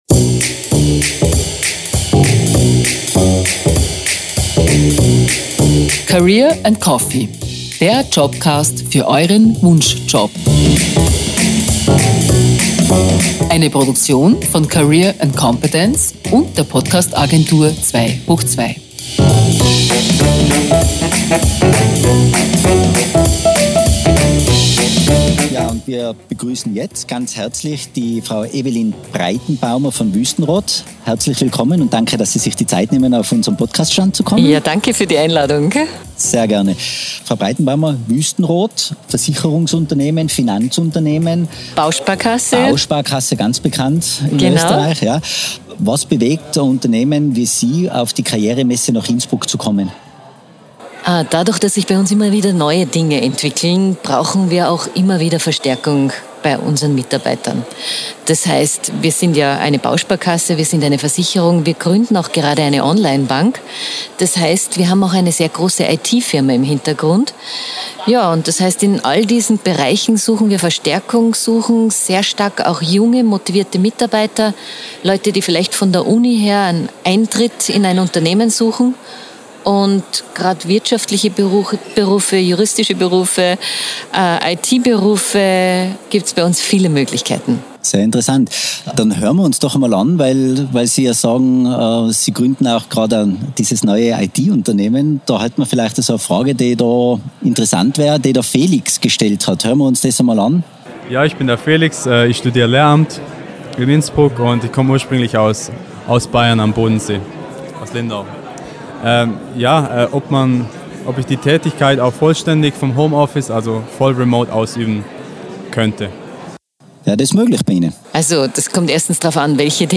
Livemitschnitt von der Career & Competence-Messe mit
Masterlounge in Innsbruck am 4. Mai 2022.